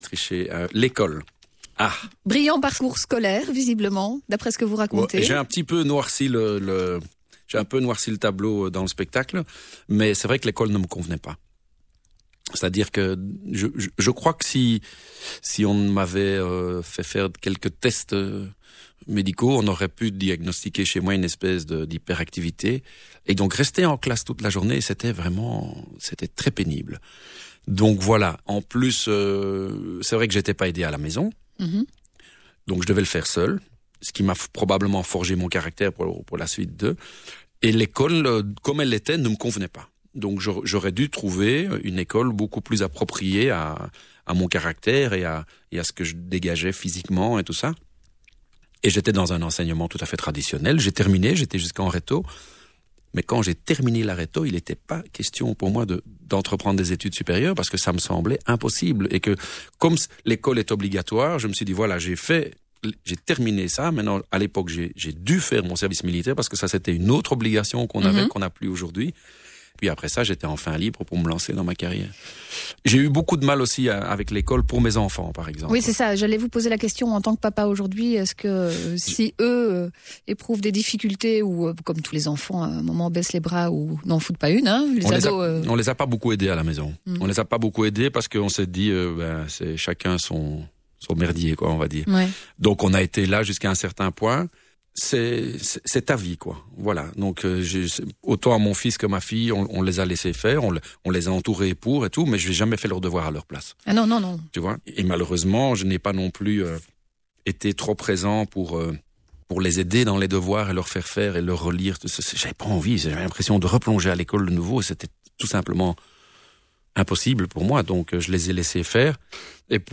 Interview reprise de l’émission « Les petits papiers » sur Vivacité le 26/01/2014.
Interview de Marka sur l’échec scolaire